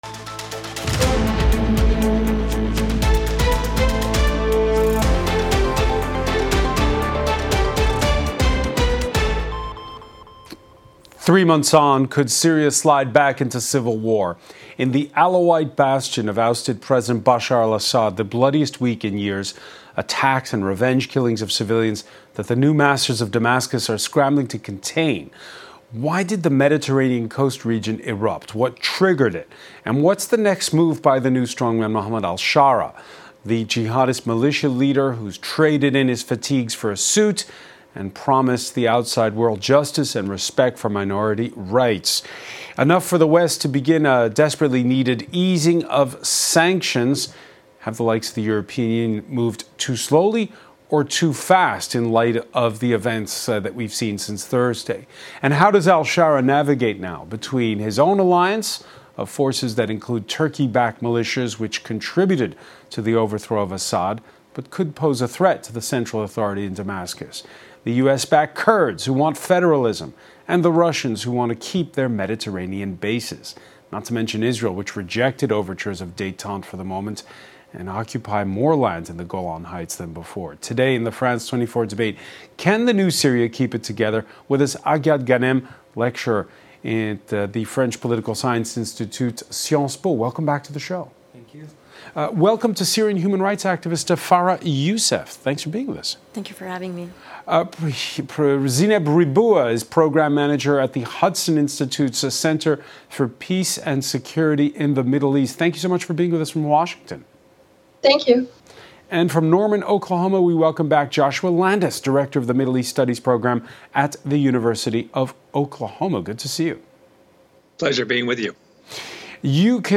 A live debate on the topic of the day, with four guests.